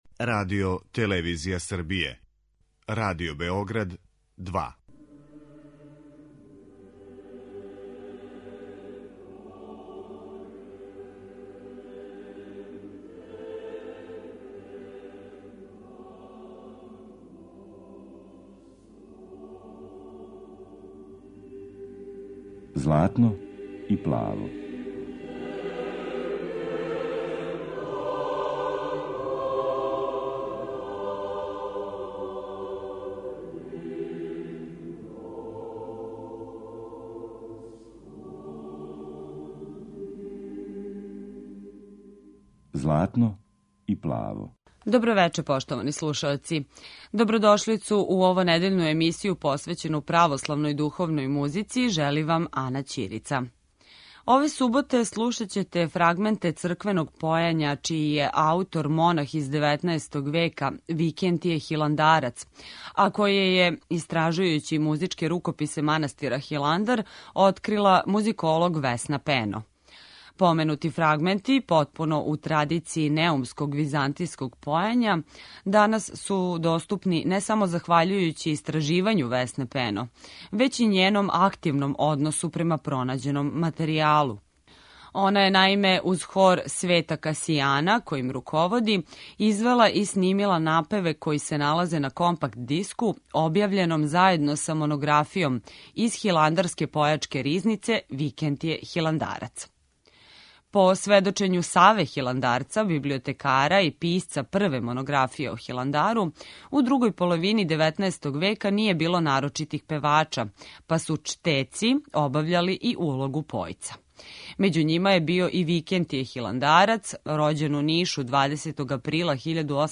Емисија о православној духовној музици